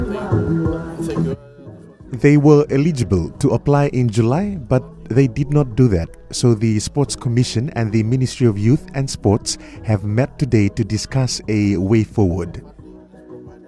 In an interview with FBC Sports, he says the Sports Commission and the Ministry of Sports will try to find a solution.